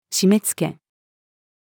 締め付け-female.mp3